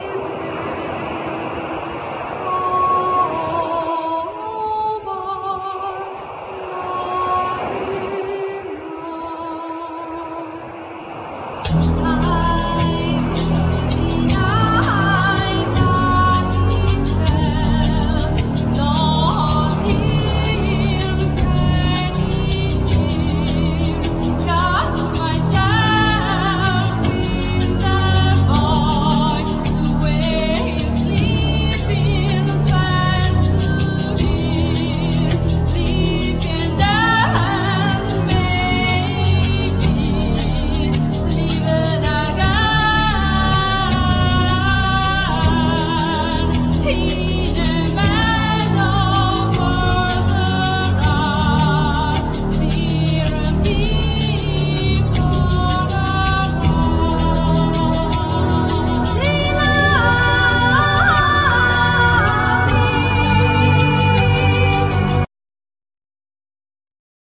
Voice,Keyboards,Percussions
Flute
Violin
Oboe
Guitar
Drums